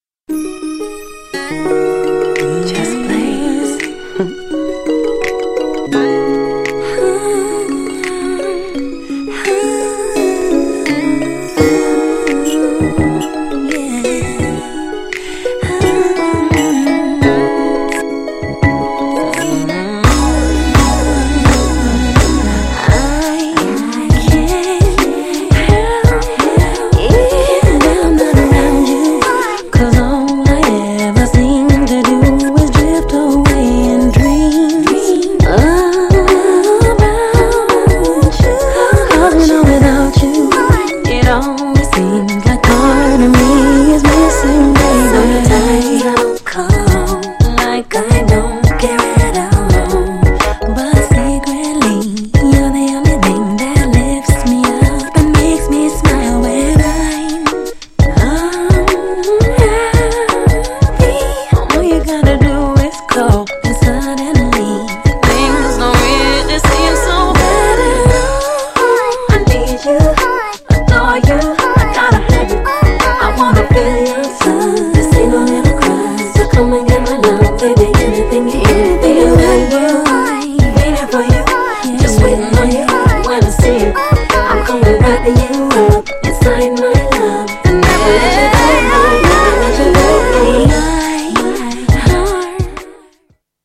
GENRE R&B
BPM 91〜95BPM
HIPHOPテイスト
SMOOTH_R&B
メロディアスR&B # 女性VOCAL_R&B